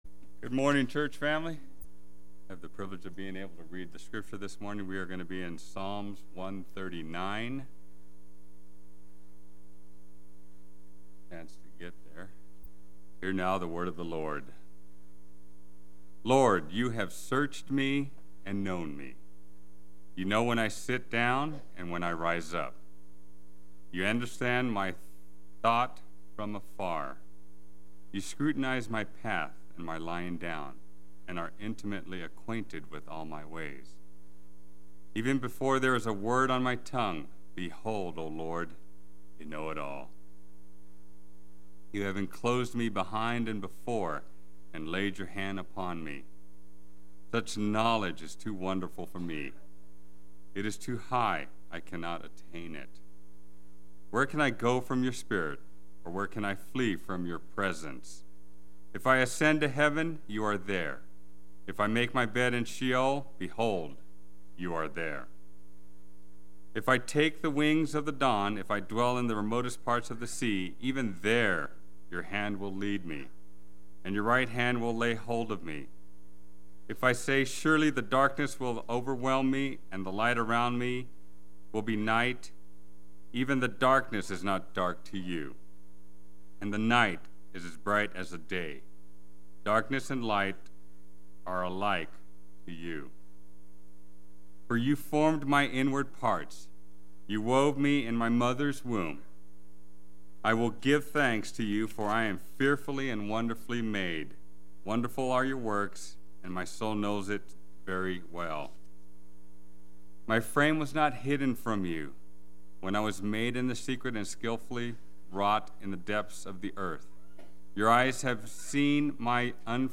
Play Sermon Get HCF Teaching Automatically.
Part 2 Sunday Worship